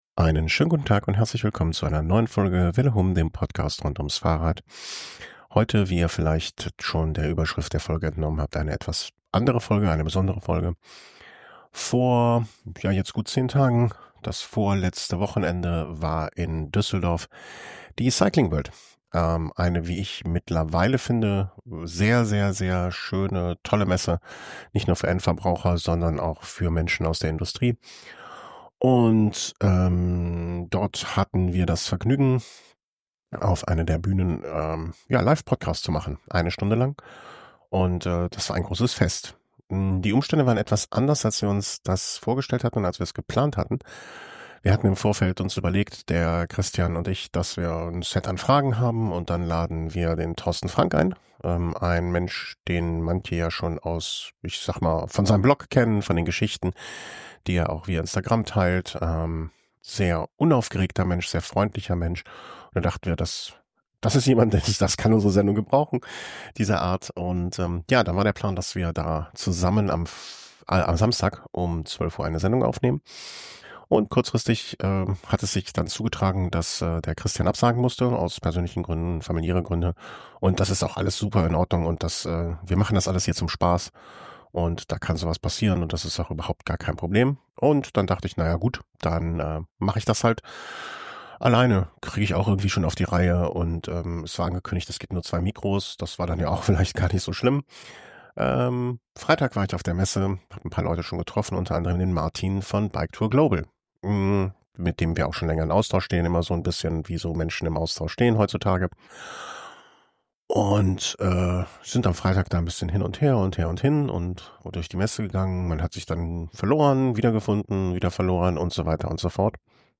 Velohome 454 Live auf der Cyclingworld Düsseldorf ~ Radsport Podcast
velohome-454-live-auf-der-cyclingworld-duesseldorf.mp3